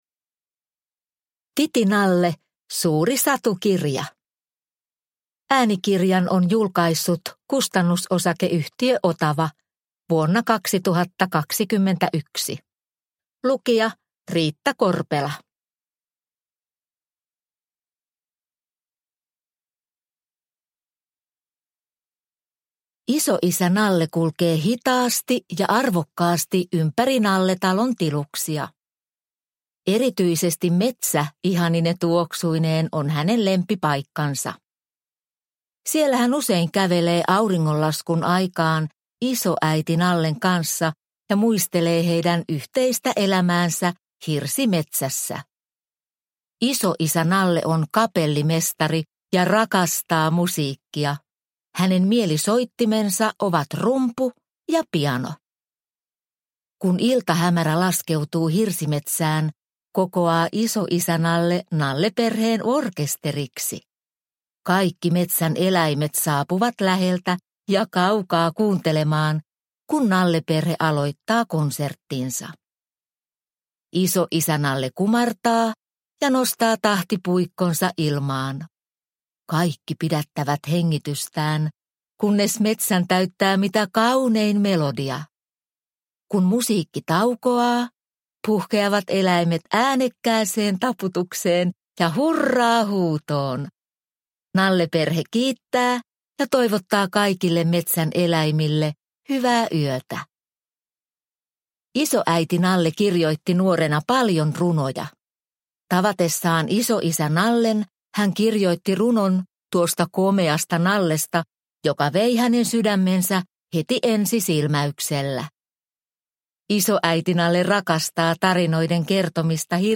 TI-TI Nalle Suuri Satukirja – Ljudbok